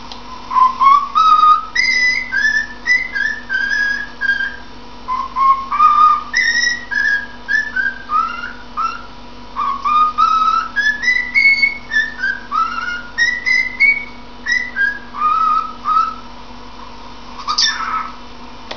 ボコちゃんの演奏会
ボコはよく持ちあがった紙の下に頭をいれて歌い始めます。
（小さな世界）   もうちょっとのところで、まだ未完成。